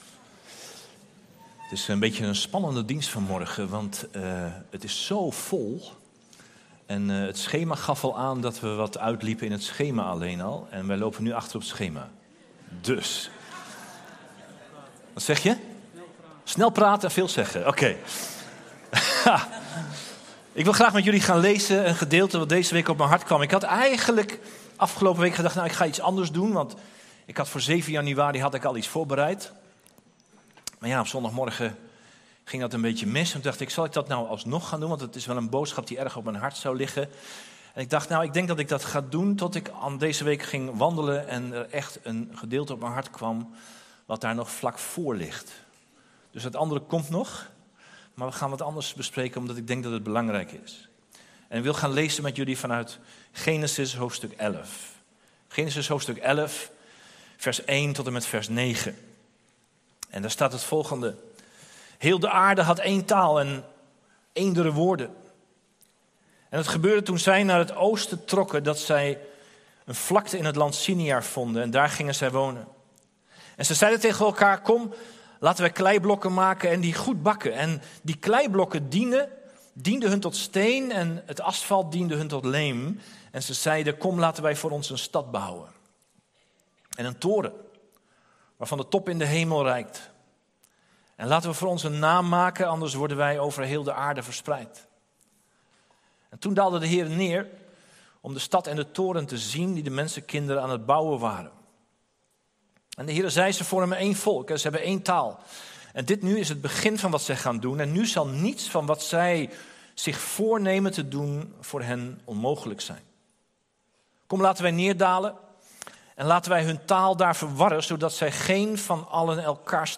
Gen 12:1-2 Soort Dienst: Reguliere dienst « Jagen naar waarheid